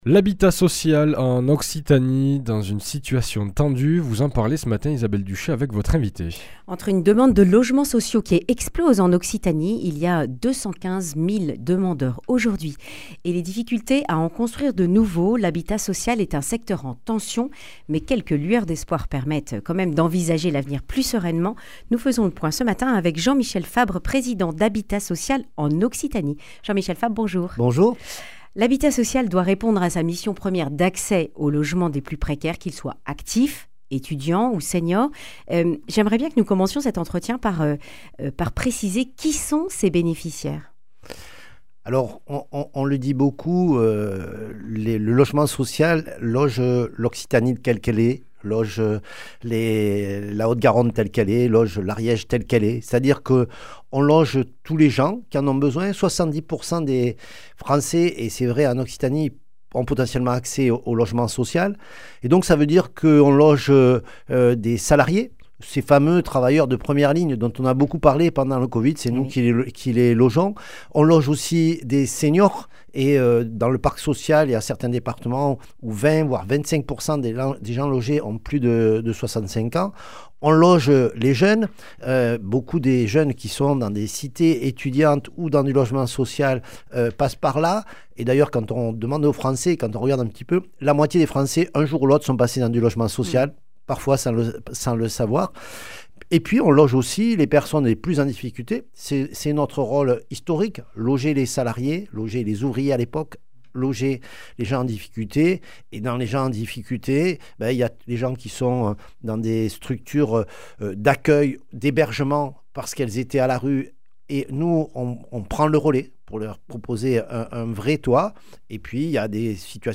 Accueil \ Emissions \ Information \ Régionale \ Le grand entretien \ Habitat social en Occitanie : des avancées positives dans un secteur en (...)